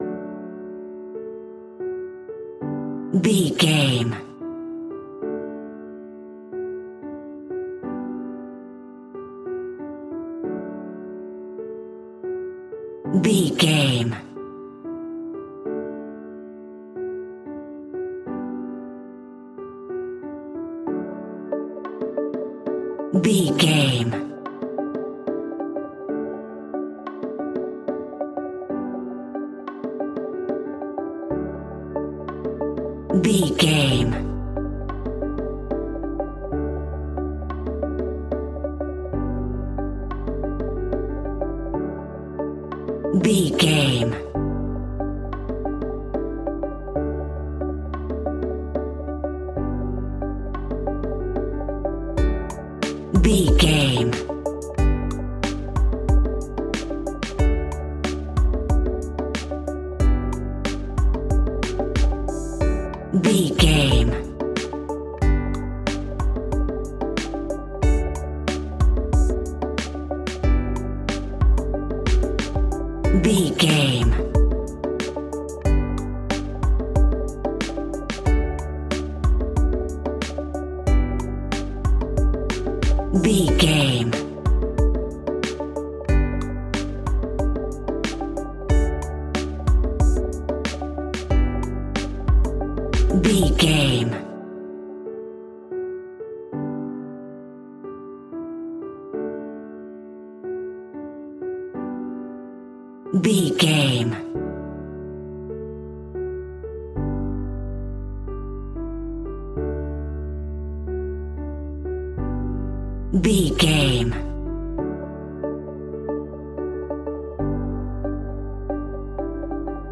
Ionian/Major
D
pop rock
indie pop
energetic
upbeat
groovy
guitars
bass
drums
piano
organ